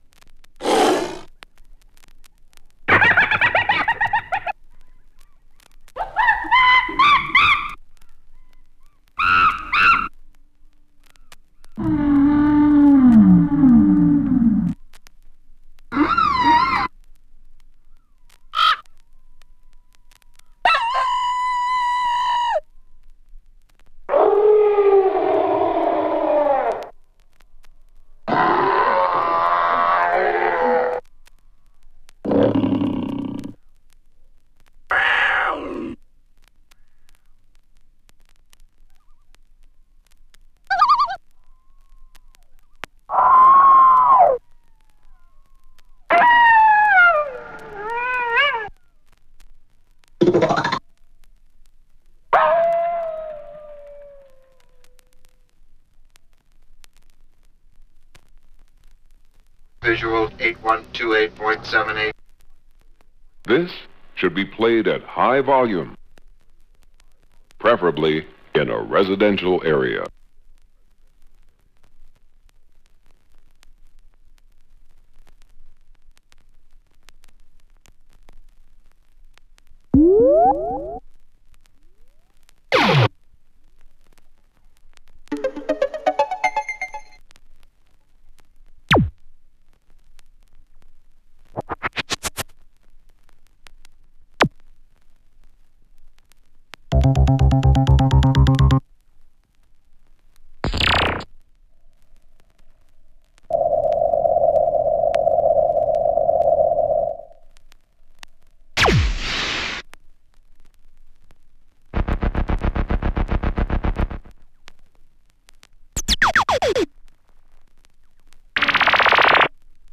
声ネタ・効果音などを多数収録。